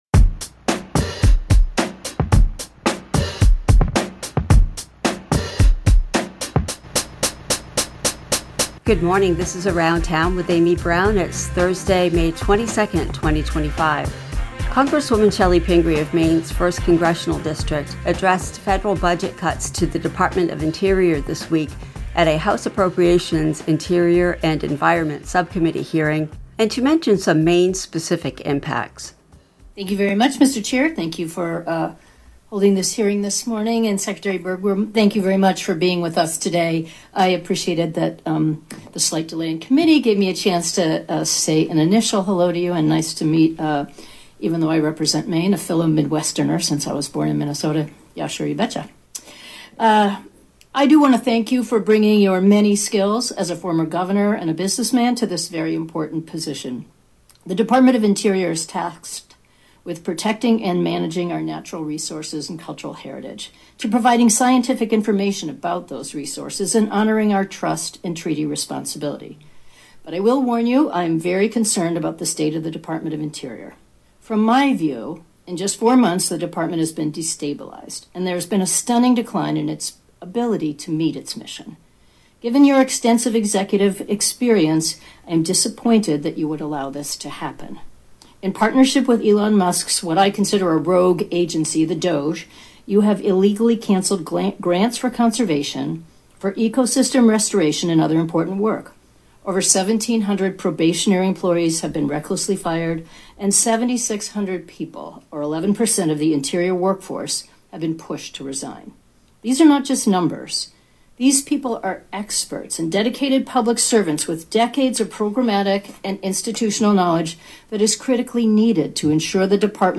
Congresswoman Chellie Pingree of Maine’s 1st Congressional District, addressed federal budget cuts to the Department of the Interior this week, at a House Appropriations Interior and Environment Subcommittee hearing — and she mentioned some Maine-specific impacts